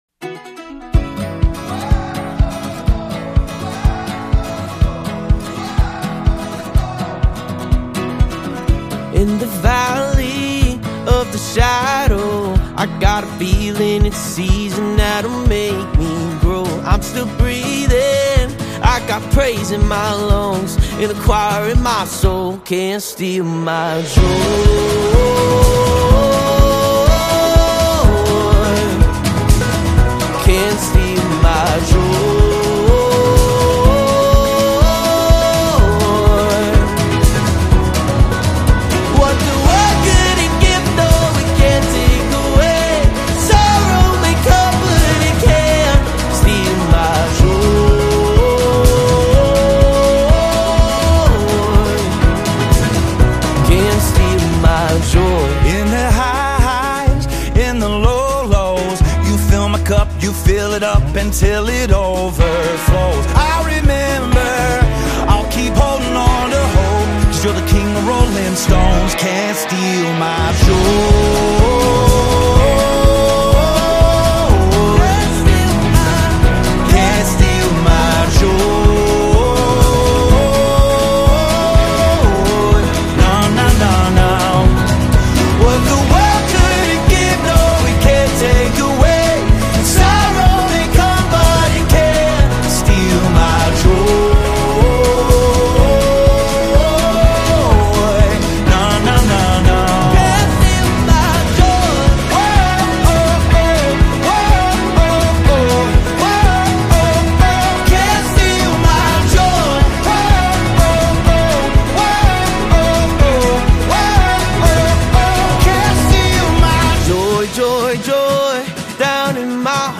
American Gospel Songs Gospel Songs